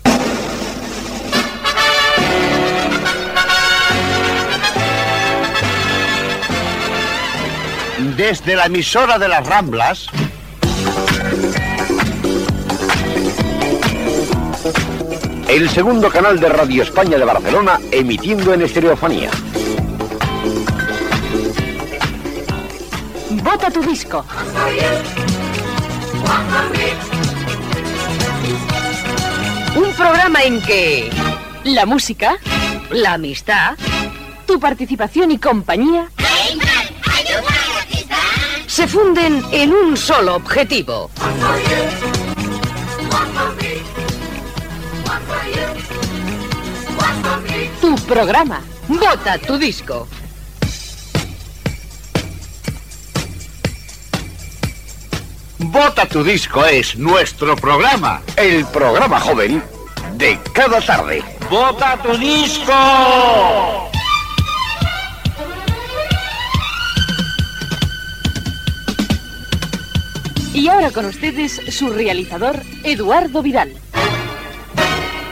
Careta del programa
Musical